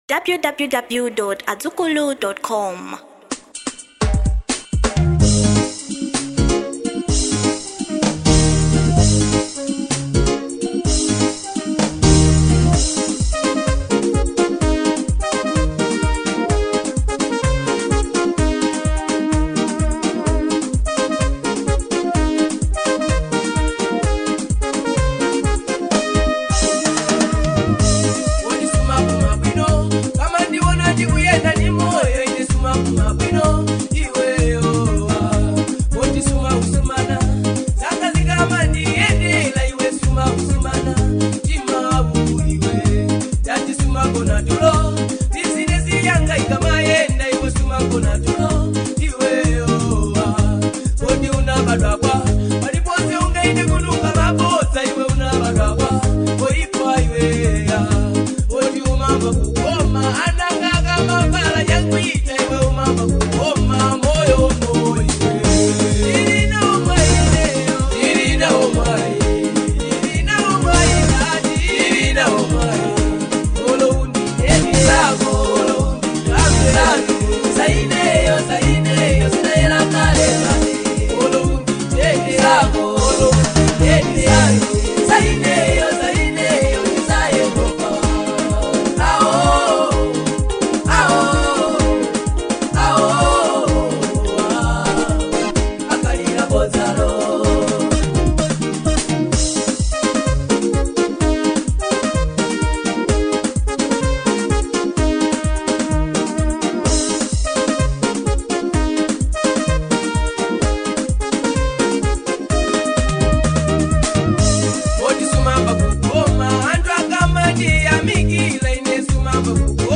Genre Local , Traditional & Manganje